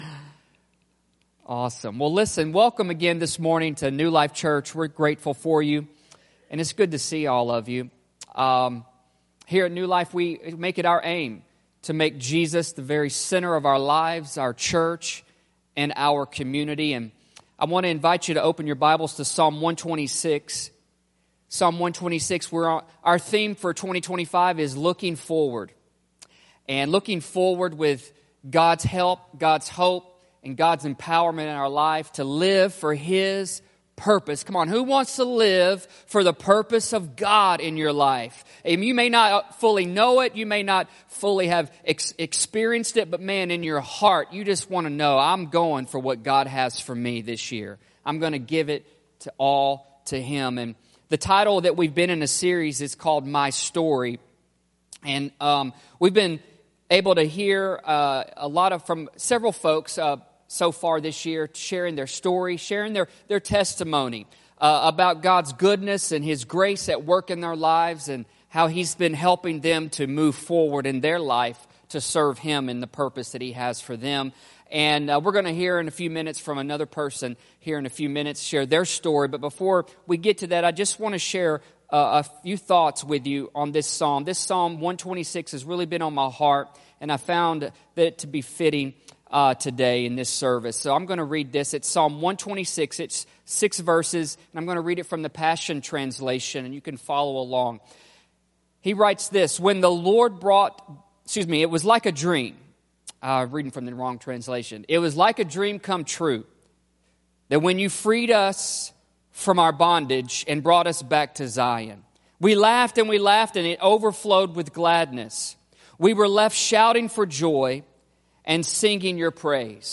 New Life Church Sermons